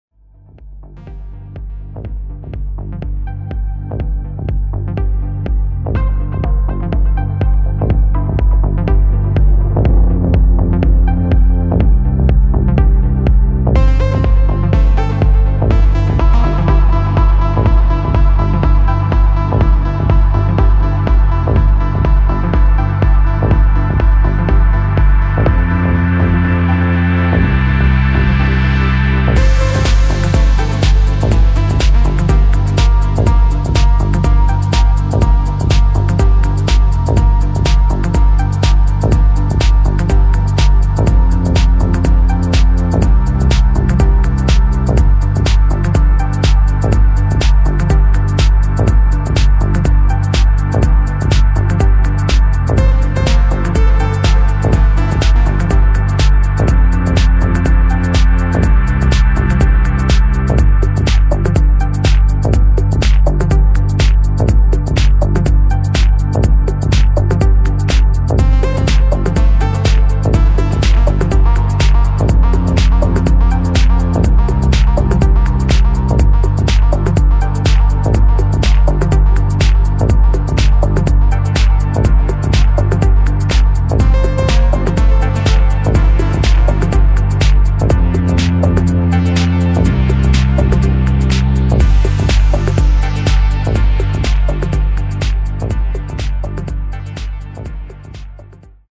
[ HOUSE | TECHNO | MINIMAL ]